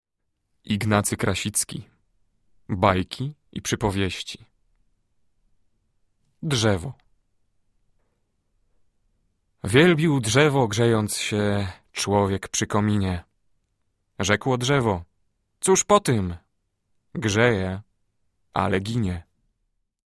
audiobook | Bajki i baśnie | Drzewo | Ignacy Krasicki | Krasicki | rymowanki | wierszyki